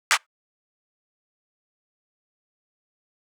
clap 5.wav